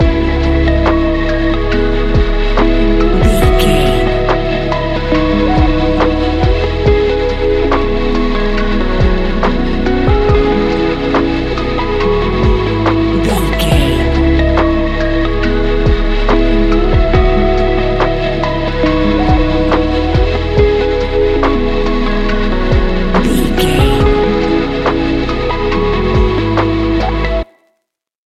Ionian/Major
C♯
chilled
laid back
Lounge
sparse
new age
chilled electronica
ambient
atmospheric
morphing